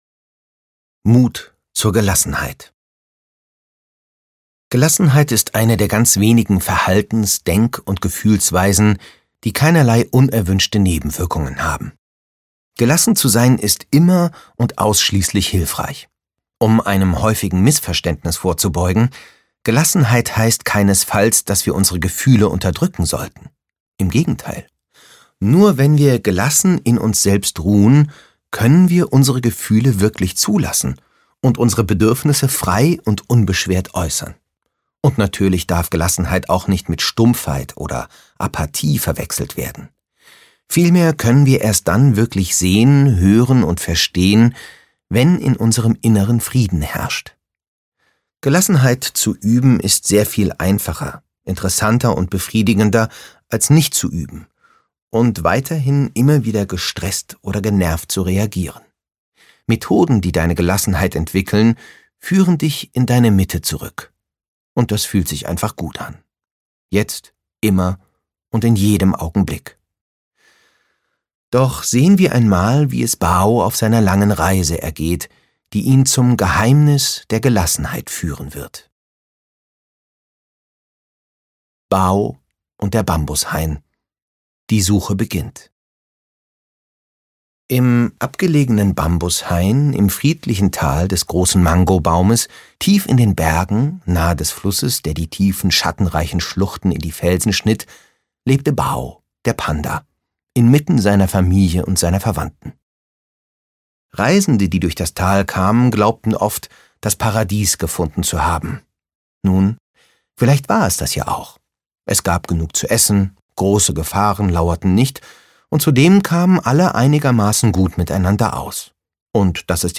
Bao, der weise Panda und das Geheimnis der Gelassenheit - Aljoscha Long - Hörbuch